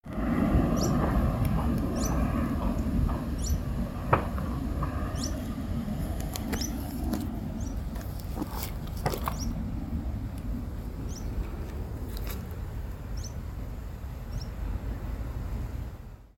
Sharp-billed Canastero (Asthenes pyrrholeuca)
Location or protected area: Reserva Ecológica Ciudad Universitaria - Costanera Norte (RECU-CN)
Condition: Wild
Certainty: Recorded vocal